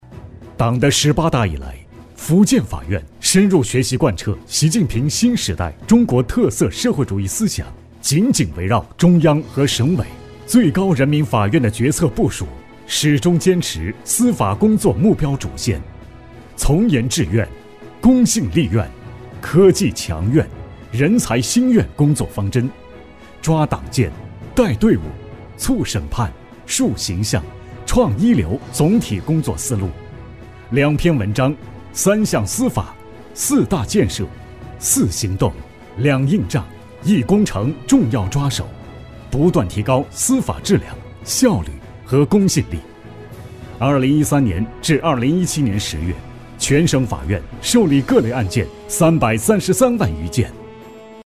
激情力度 企业专题,人物专题,医疗专题,学校专题,产品解说,警示教育,规划总结配音
浑厚男中音，偏年轻化。